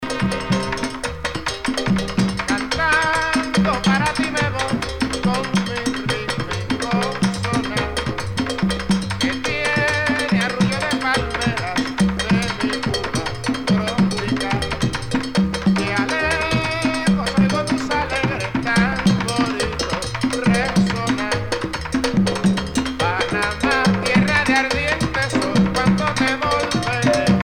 danse : conga
Pièce musicale éditée